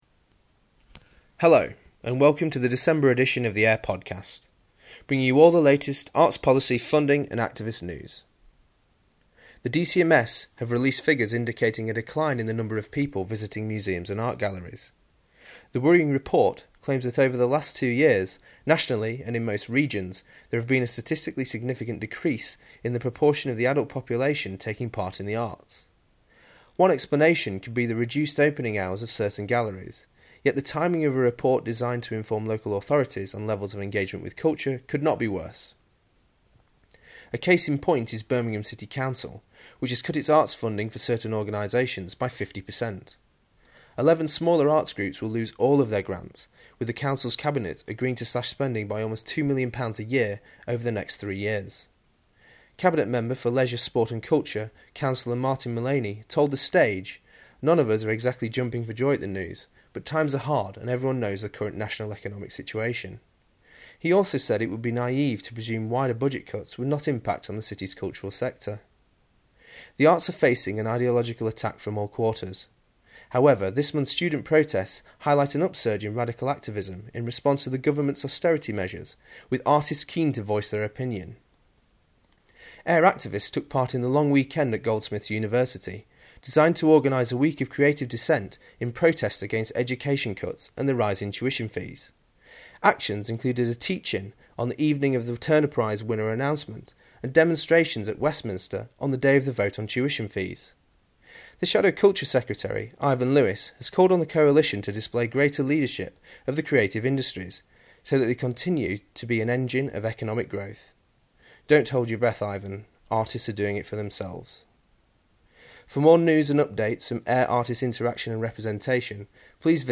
My report on all the latest arts policy, funding and activist news for AIR: Artists Interaction and Representation.